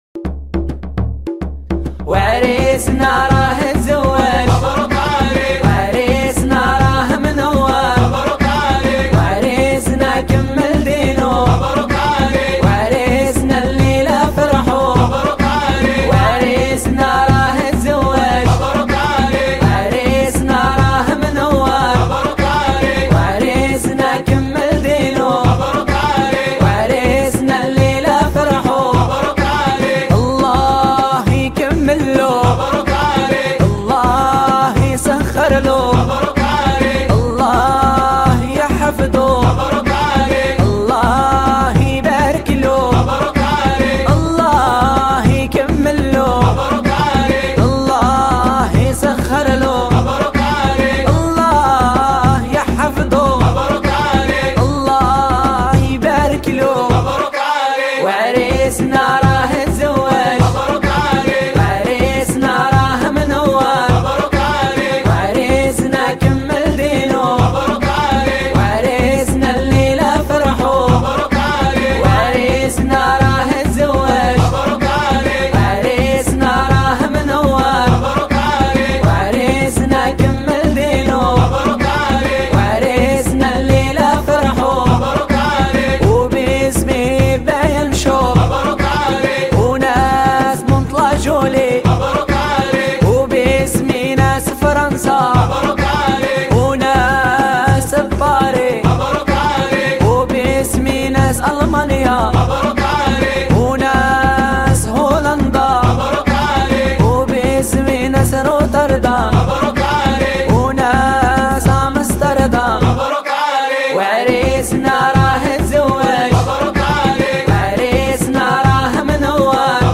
Anasheed 100% duff
Anasheed is islamitische gezang, met beperkte woorden en beperkte instrumenten.
Anasheed-zawaaj.mp3